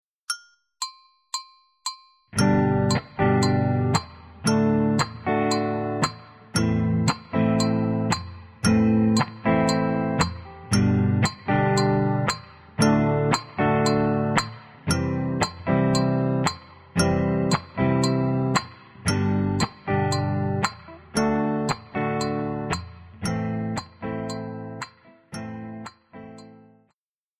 W nagranych przykładach będą pojawiać się, omówione wcześniej, martwe nuty na 2, 4:
G                    D                     Am                  C
* Uwaga! W zapisie mamy ćwierćnuty (nuty na 1, 3 i pauza na 4).